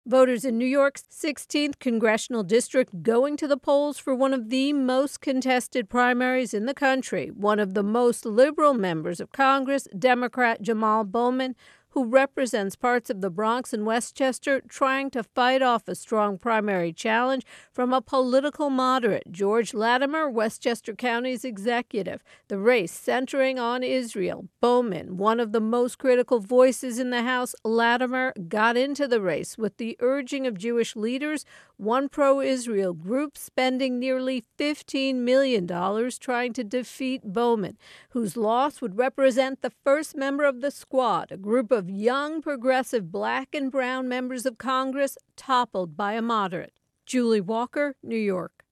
reports on a very expensive Democratic primary race in New York being closely watched around the country.